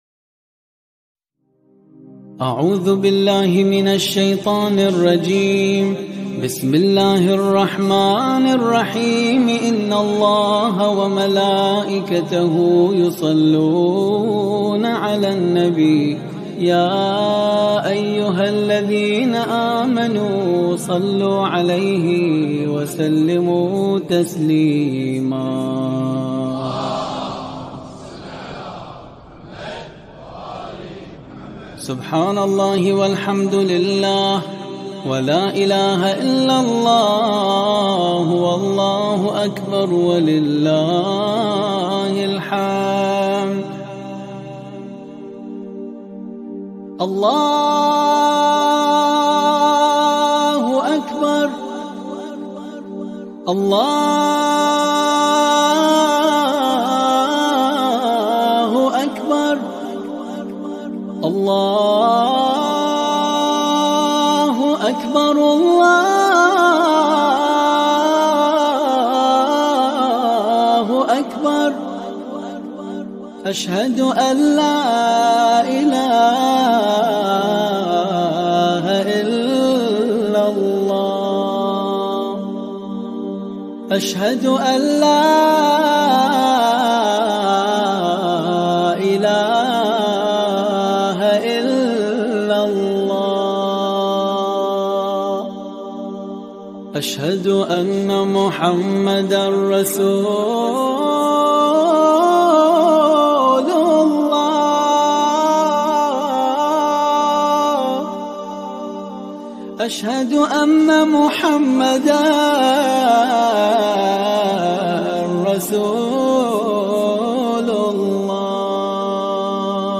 صوت زیبای اذان
موذن